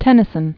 (tĕnĭ-sən), Alfred First Baron Tennyson.